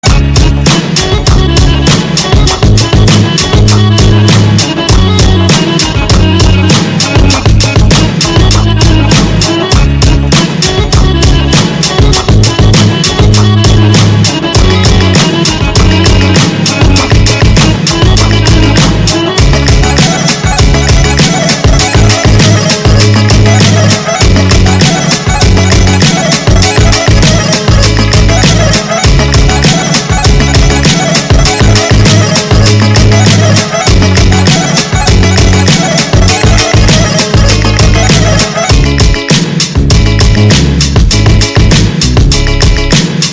• Качество: 192, Stereo
громкие
Хип-хоп
веселые
саундтреки
мощные басы
быстрые
кастаньеты